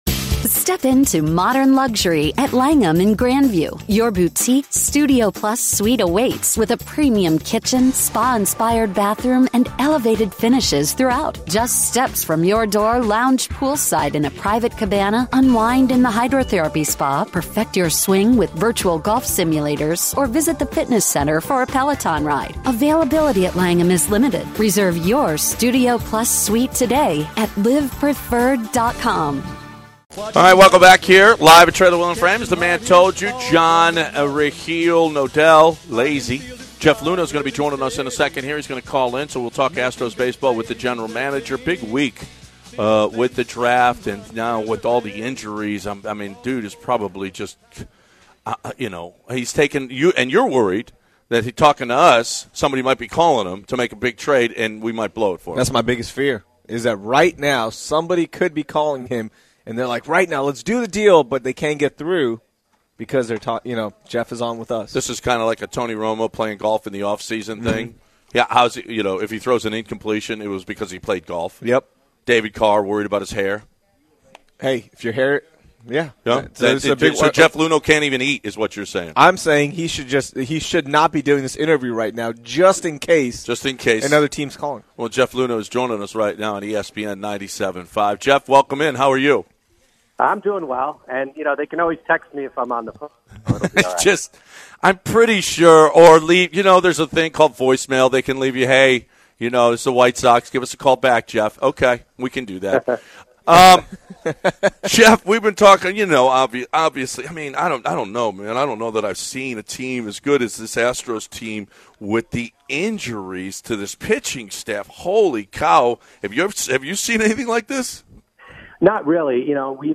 Jeff Luhnow Interview